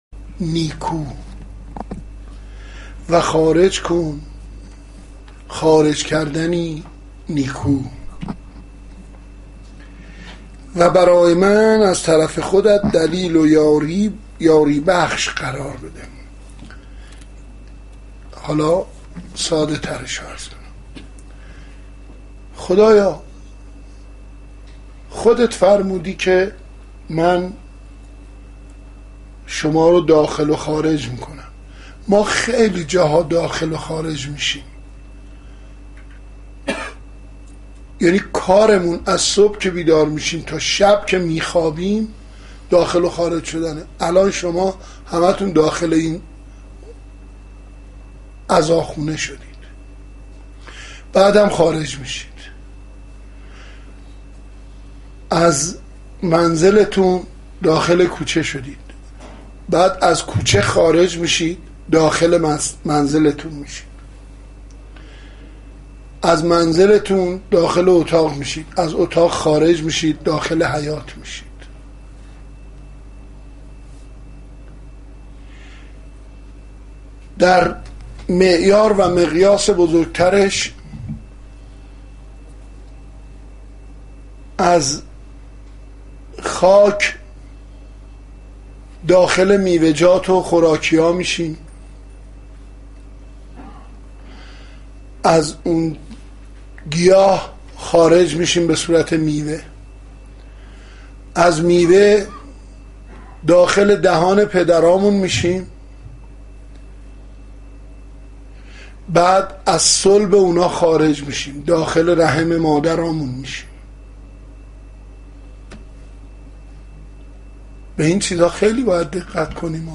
خیمه گاه - حسینیه کربلا - شب چهارم فاطمیه (رفسنجان) - 12 اردیبهشت 1389